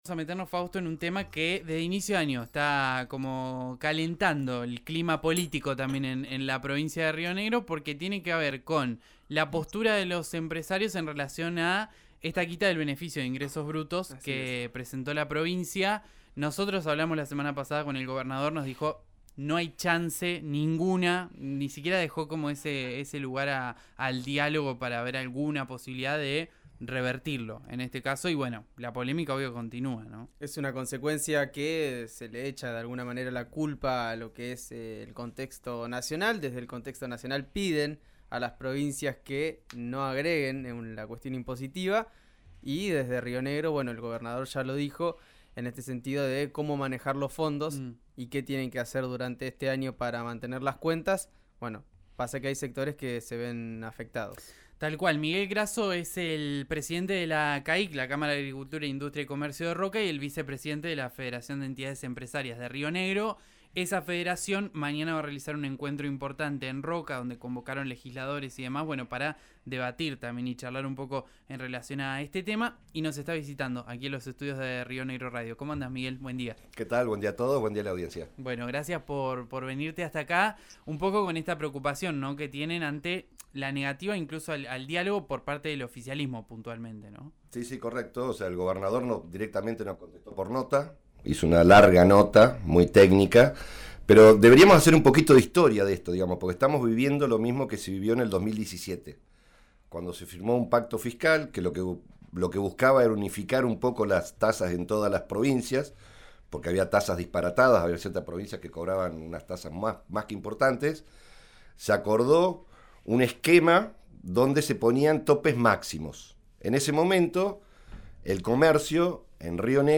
durante una visita a los estudios de RÍO NEGRO RADIO.